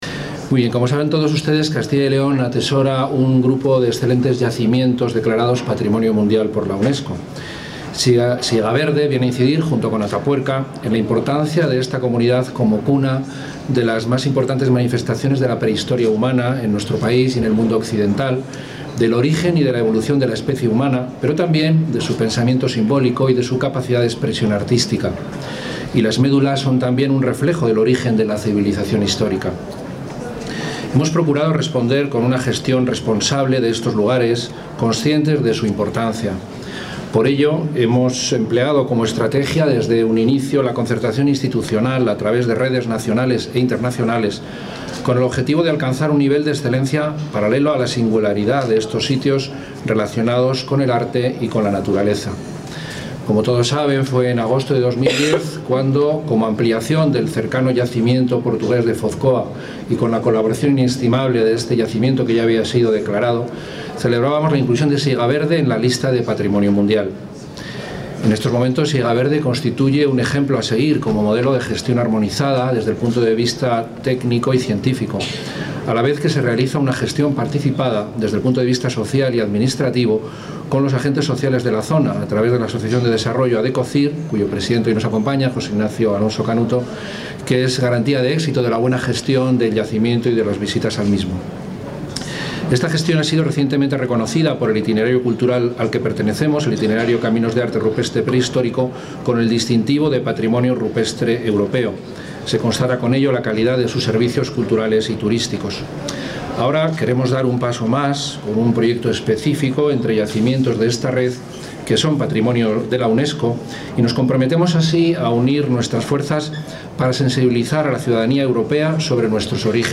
Director general de Patrimonio Cultural.